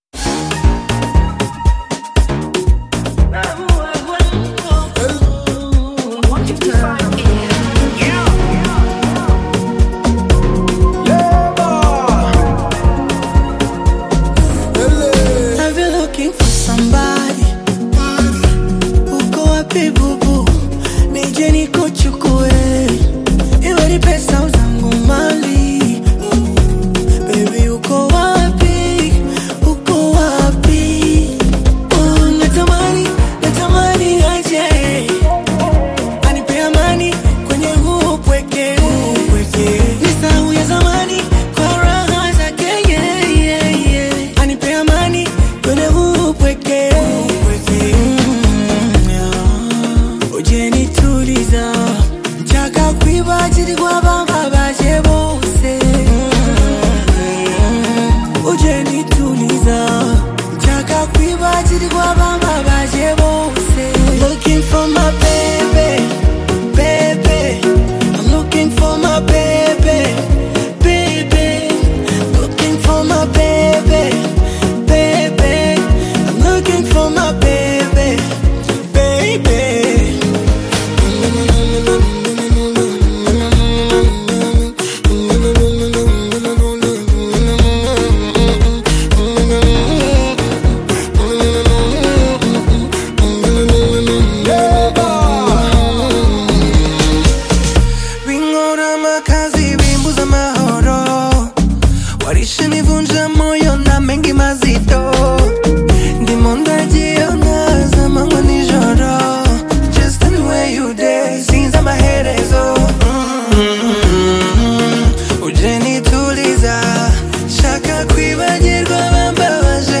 smooth Afrobeats/Bongo Flava single
Genre: Bongo Flava